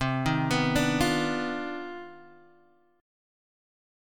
CmM11 chord